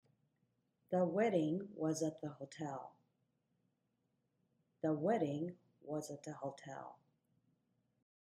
Weddingが一番強調され、
が軽く素早く発音されているのが